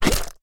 mob / slime / attack1.ogg
attack1.ogg